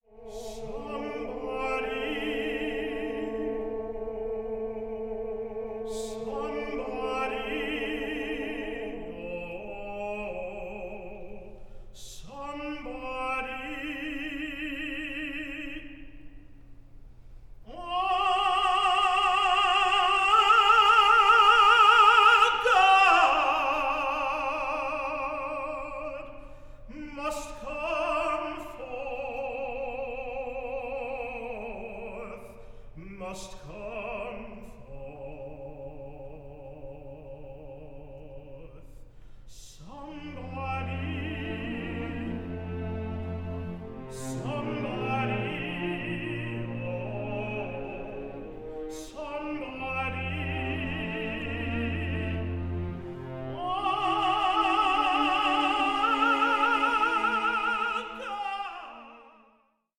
a contemporary opera on racial injustice in the US today